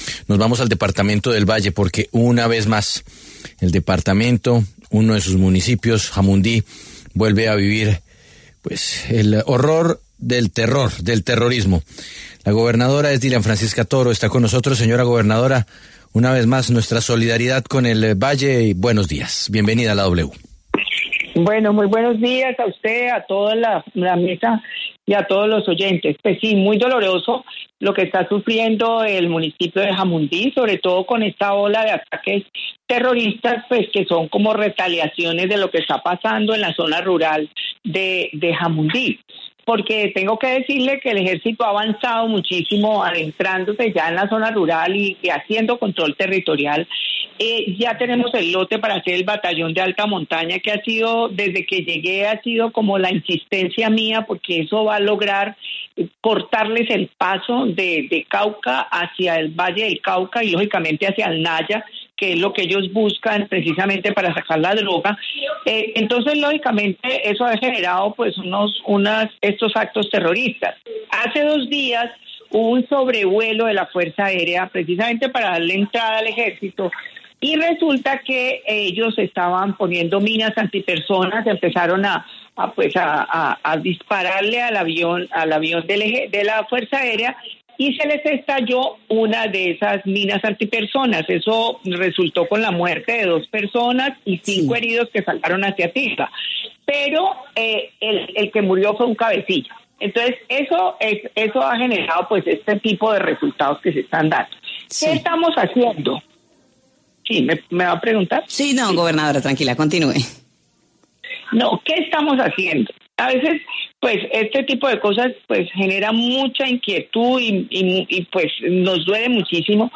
La gobernadora del Valle del Cauca, Dilian Francisca Toro, pasó por los micrófonos de La W y se refirió al respecto, asegurando que es “muy doloroso lo que está sufriendo el municipio de Jamundí”.